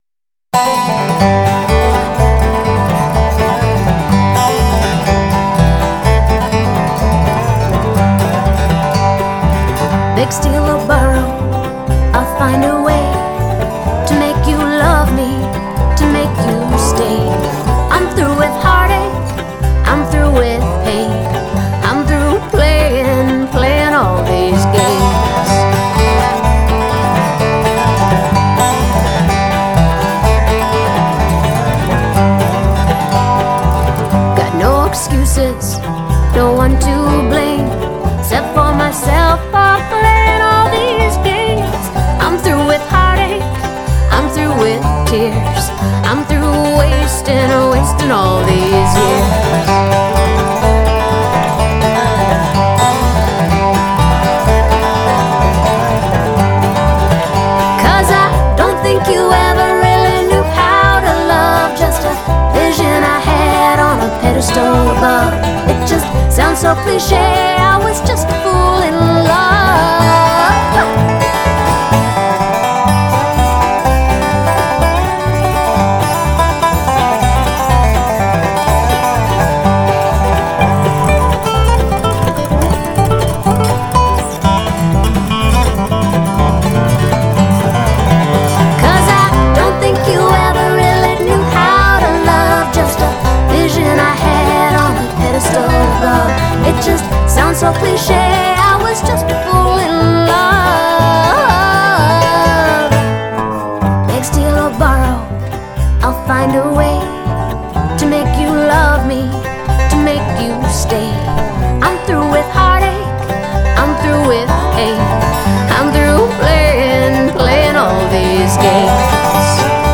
Original Contemporary Bluegrass, Swing, Folk & Instrumentals
Vocals
Guitar & Banjo
Dobro
Bass.